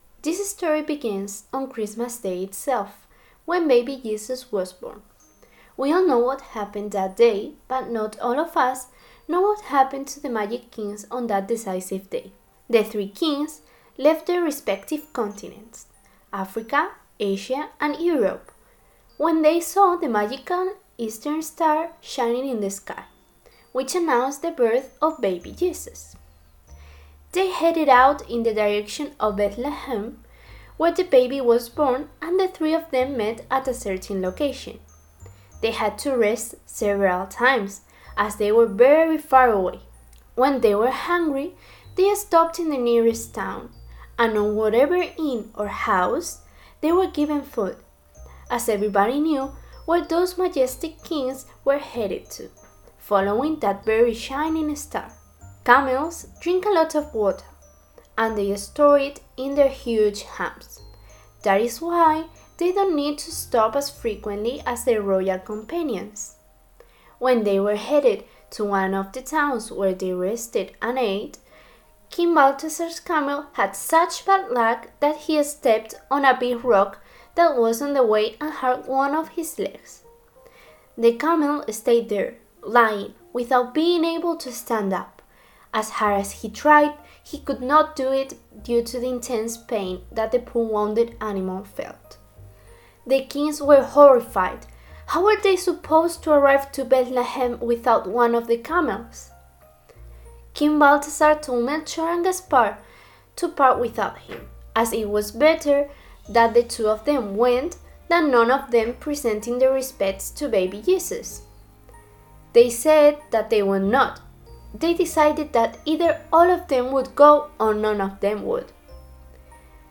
Audiobook Audiobook The accident of the royal camel in english.
English version of the audiobook.